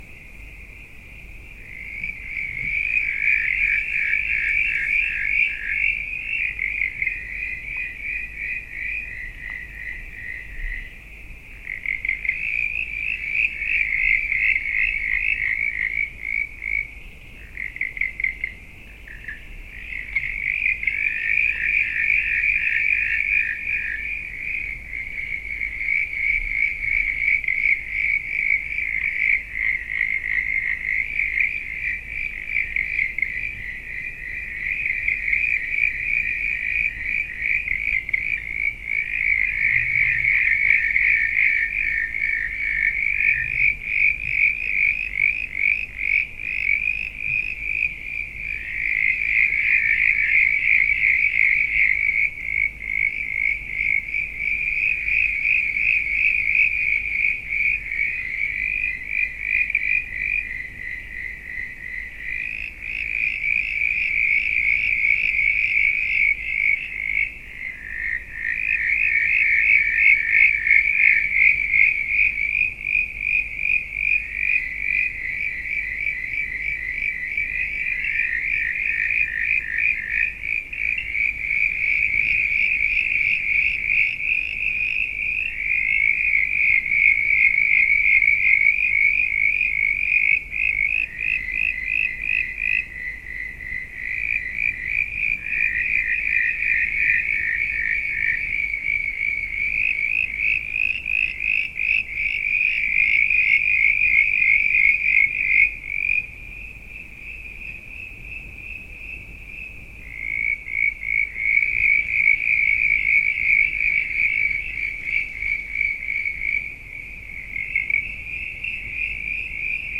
布鲁尼岛的氛围2
描述：Crescent Honey Eater（埃及鸟）。 2015年7月31日上午7点8分，在塔斯马尼亚州布鲁尼岛的Inala，使用Rode NT55在PMD 661上录制。
标签： 布鲁尼 塔斯马尼亚岛 鸟的歌声
声道立体声